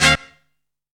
SHRILL STAB.wav